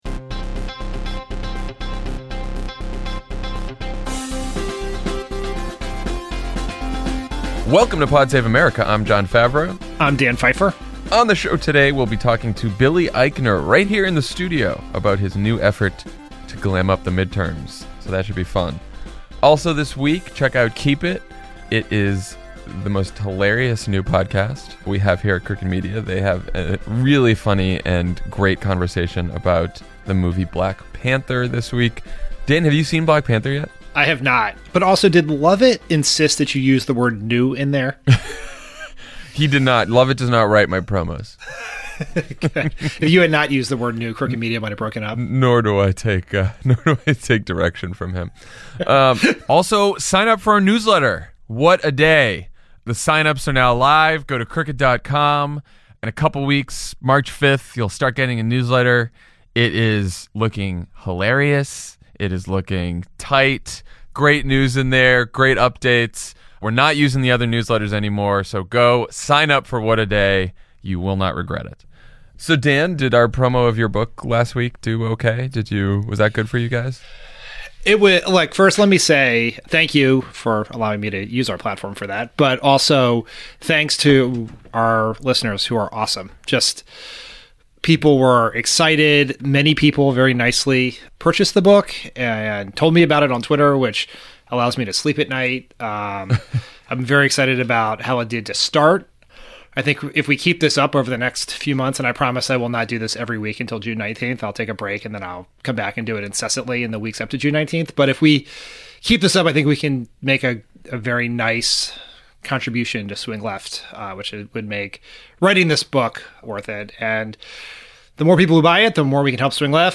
In the aftermath of the Parkland shooting, a new student movement is born, the Republican/NRA conspiracy nuts go crazy, and Republican politicians start feeling the pressure. Then Jon and Tommy talk to comedian Billy Eichner about Glam Up the Midterms, his new effort to register young voters.